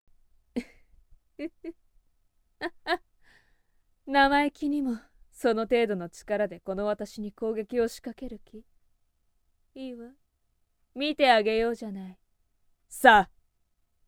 【サンプルボイス】